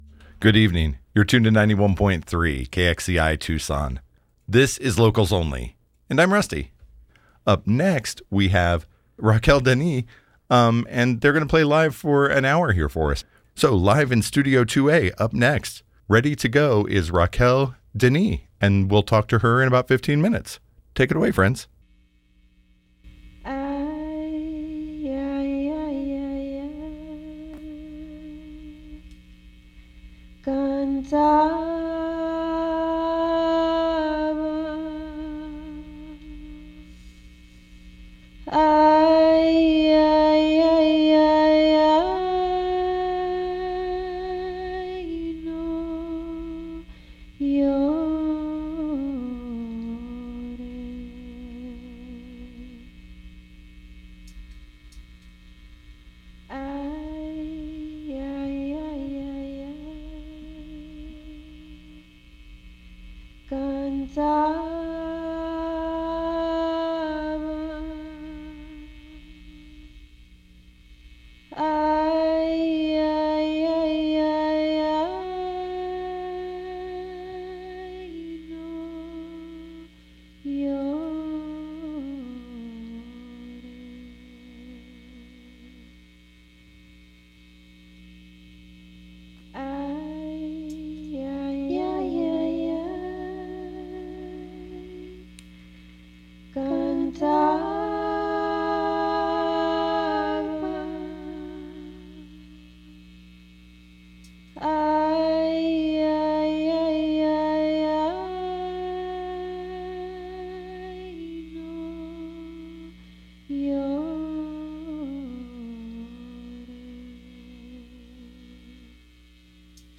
Listen to the live performance + interview here!
live in Studio 2A
folk , indie-folk
Singer/Songwriter